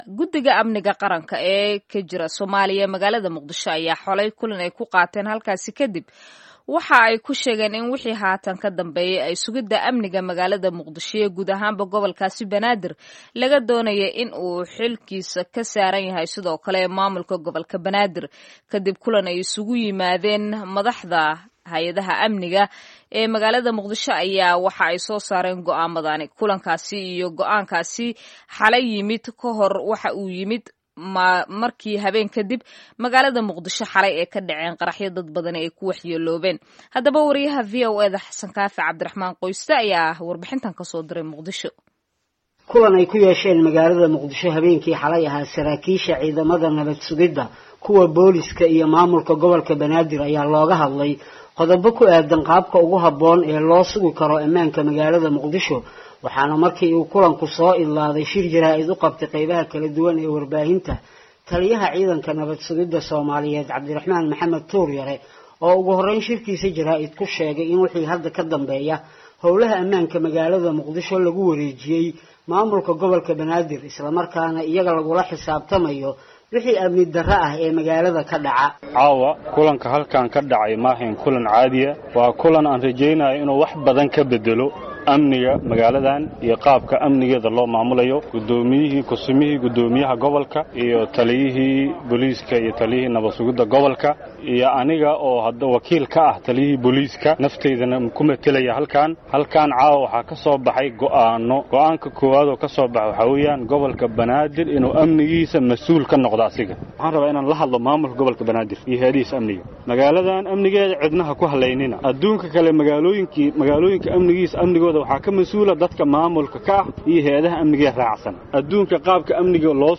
Dhagayso Tuur Yare iyo warbixin ay VOA ka diyaarisay